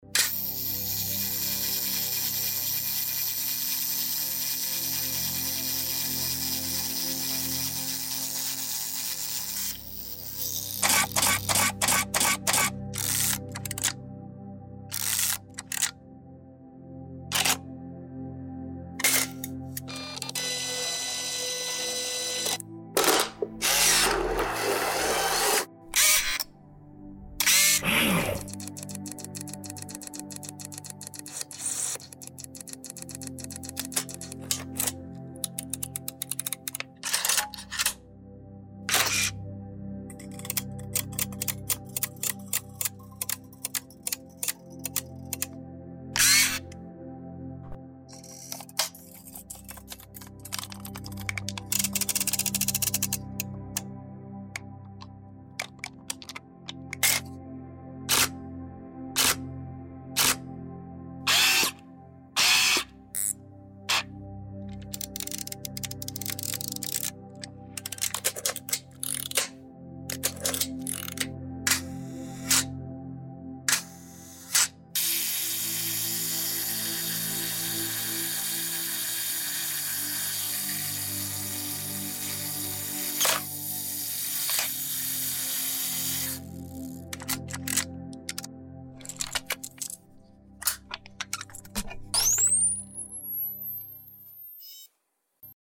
[音效]100个摄像机单反零件快门拍摄自动对焦无损音效素材 Camera Shutter
包含100个WAV音效文件，包含各种单反照相机工作时的零件、摄像机快门、拉动胶片推进杆、处理噪音、自动定时器、自动对焦，胶卷按钮和旋转拨盘等各种音效，每个音效文件时长半分钟左右都是采用Sound Devices 702，Rode NTG3和Oktava MK012录制的。
音效试听（下载后无背景声）：